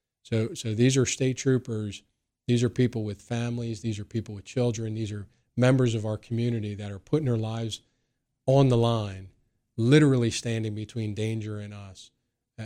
In an interview on Indiana in the Morning, DA Manzi praised the responding troopers as heroes who ran toward danger to protect the community.